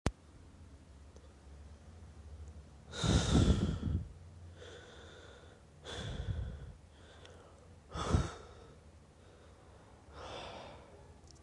描述：慢动作
Tag: 100 bpm Blues Loops Harmonica Loops 826.96 KB wav Key : Unknown